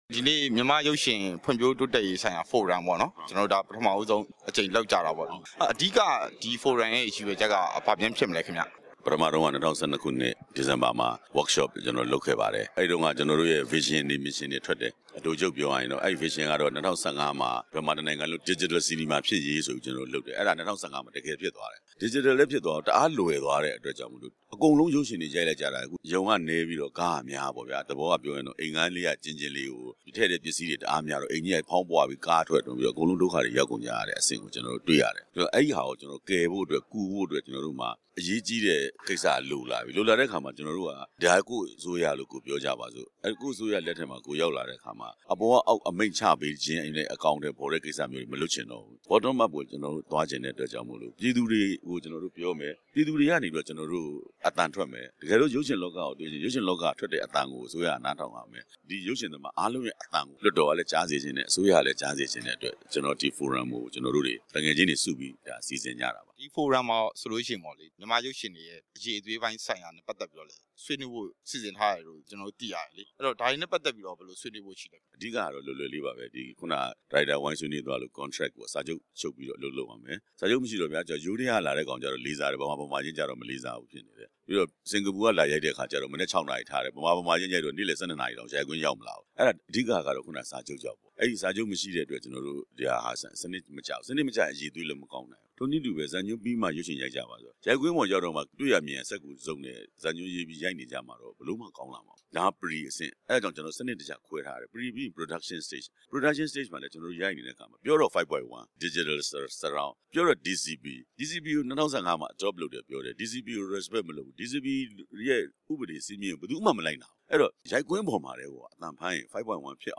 ဦးဇာဂနာနဲ့ မေးမြန်းချက်